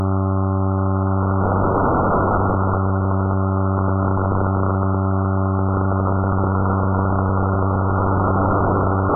Fmcw_96Hz_1nfm.mp3